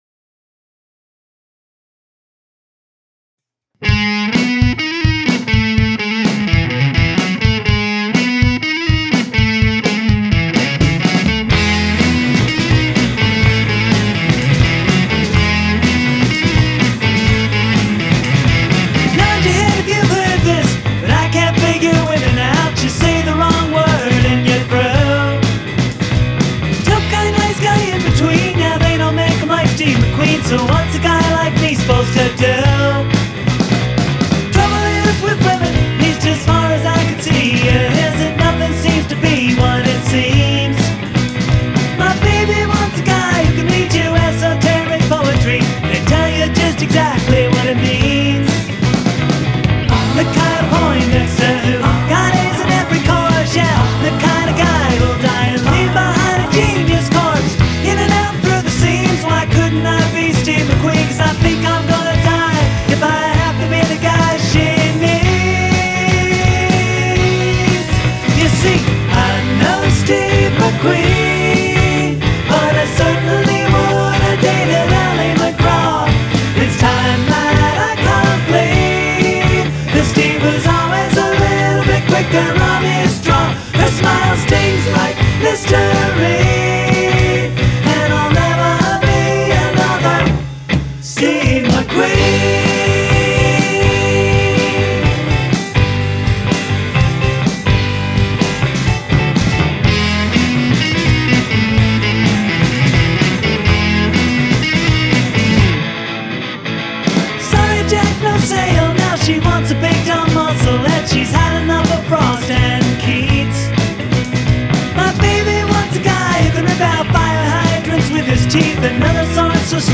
I agree the vocal is a too loud.
Don't hate me either but if it were me I might also revisit the way the reverb was applied and look at the discrepancy between the relatively wet guitars and the relatively dry vocal etc. Sounds a bit off to me like they're in different rooms and it throws of...